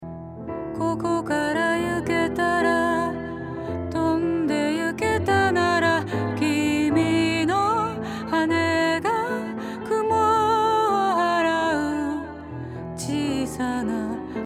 ディレイ
まず、テンポが合っているもの。
テンポに合わせている方がすっきり美しく聴こえますね。